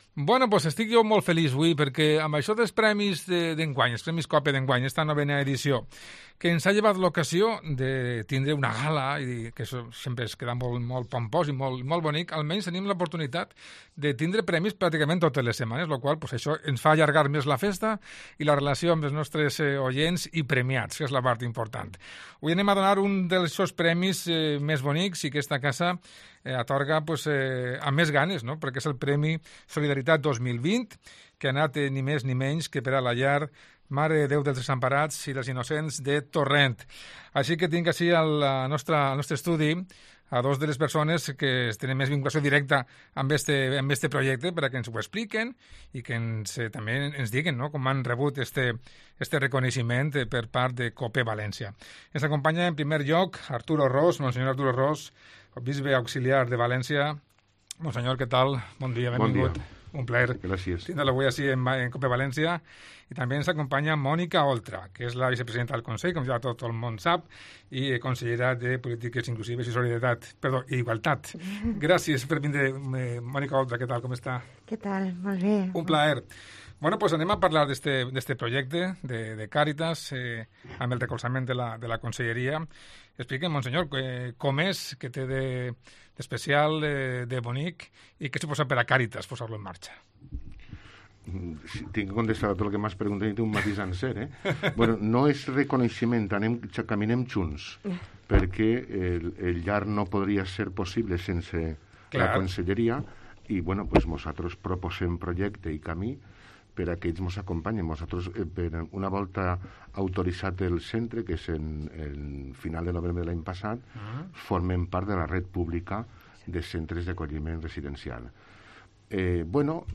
En esta ocasión ha sido el turno del premio Solidaridad 2020 que ha recaído en el hogar Mare de Déu dels Desamparats i dels Inocents de Torrent. Para recoger el galardón han estado en las instalaciones de la emisora Monseñor Arturo Ros obispo auxiliar de la diócesis de Valencia y Mónica Oltra, Consellera de Políticas Inclusivas e Igualdad.